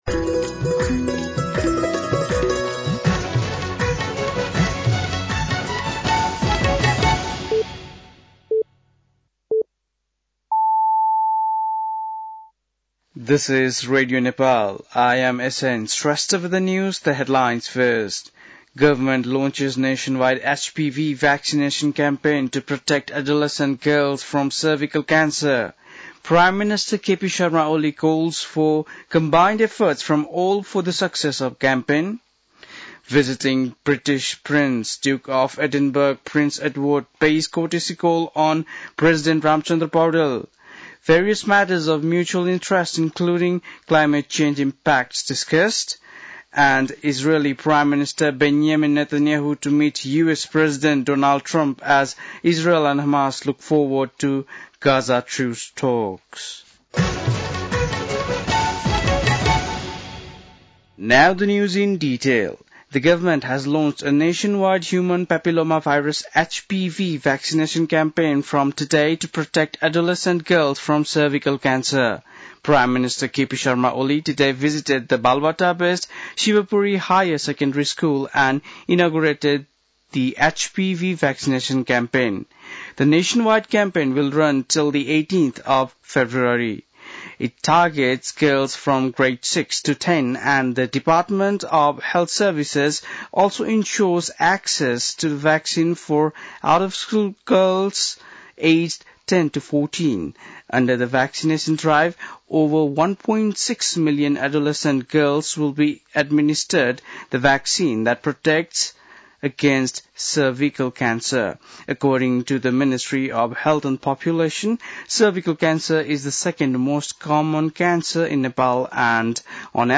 बेलुकी ८ बजेको अङ्ग्रेजी समाचार : २३ माघ , २०८१
8-PM-English-News-10-22.mp3